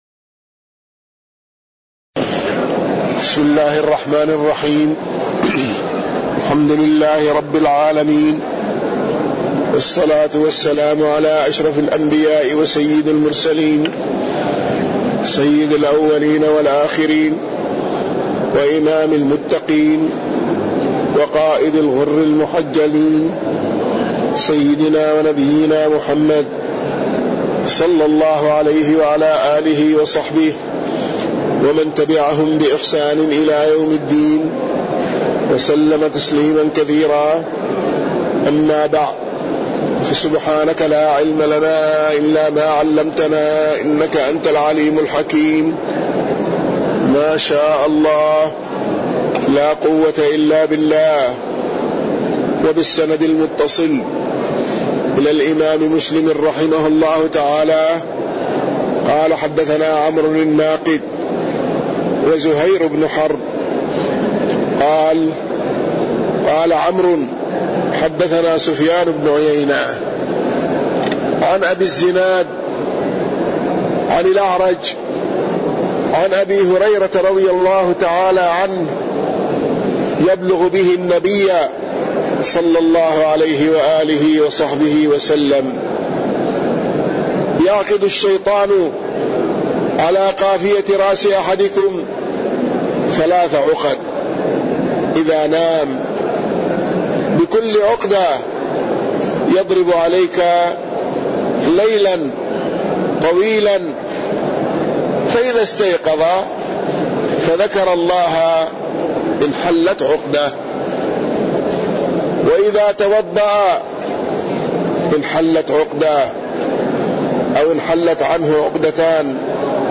تاريخ النشر ١ ربيع الأول ١٤٣١ هـ المكان: المسجد النبوي الشيخ